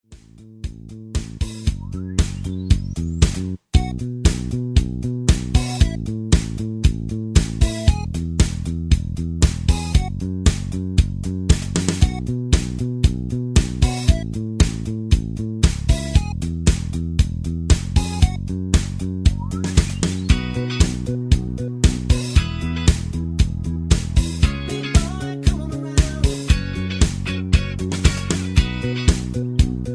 Karaoke Mp3 Backing Tracks
karaoke